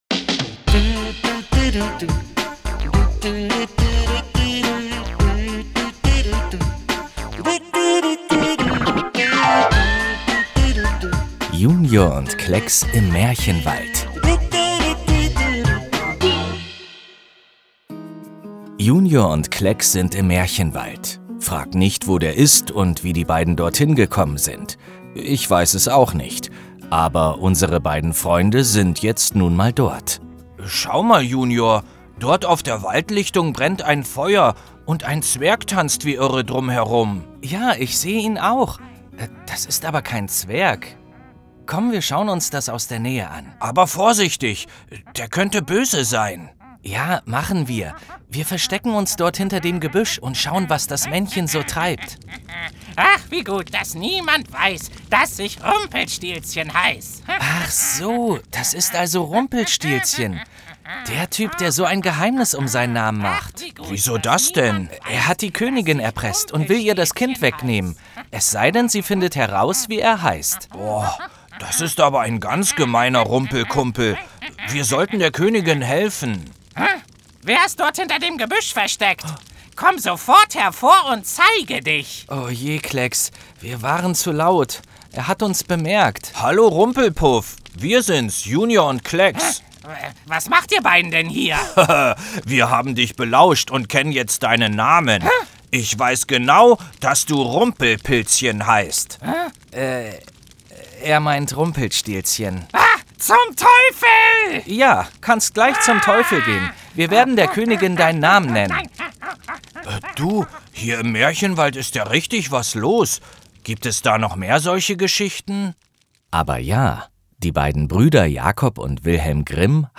Hörspiel Junior & Klexx Oktober 2024 - JUNIOR Deutschland